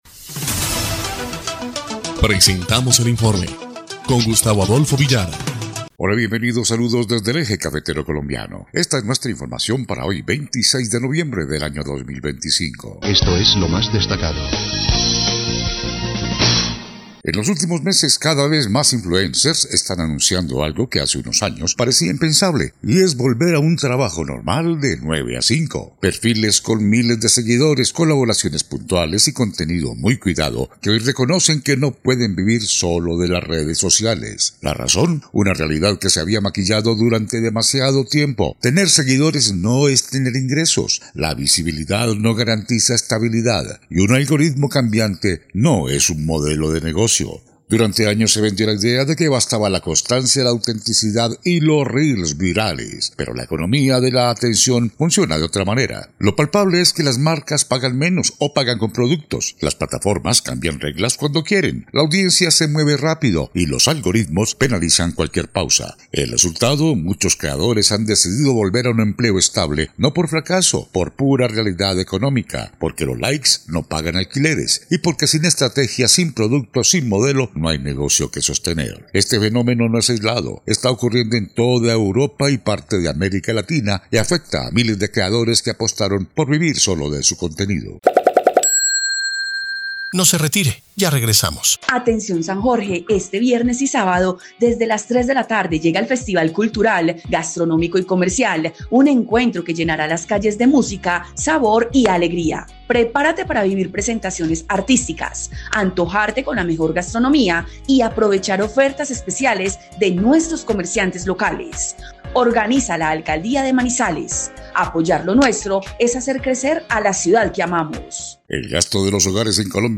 EL INFORME 2° Clip de Noticias del 26 de noviembre de 2025